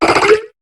Cri de Méditikka dans Pokémon HOME.